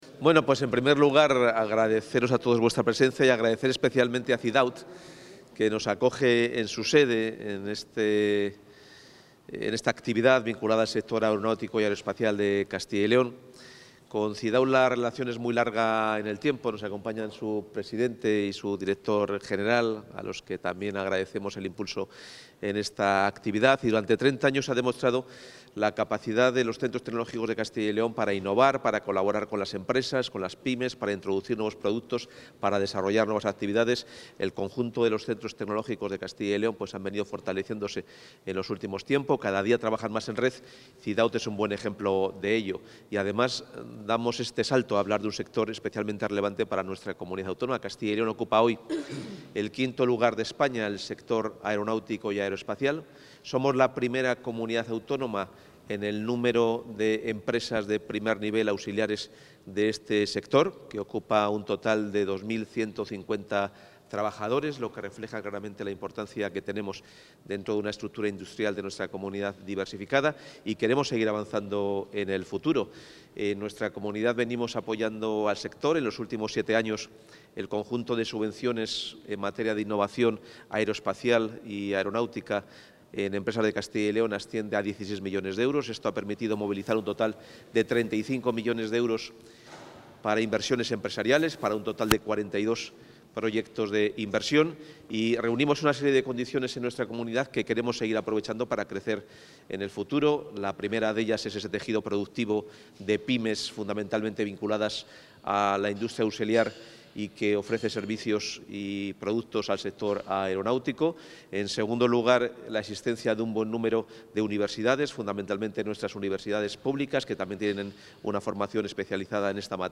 Intervención del consejero.
El consejero de Economía y Hacienda, Carlos Fernández Carriedo, ha participado en el Foro Aeronáutica y Espacio, organizado por la Fundación Cidaut en colaboración con el Instituto para la Competitividad Empresarial, dentro del programa Centratec.